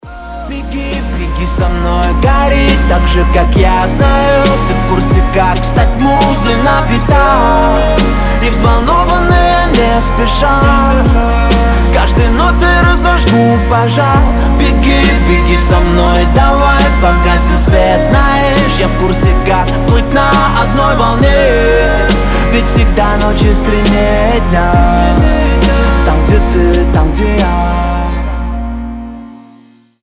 мужской голос
мелодичные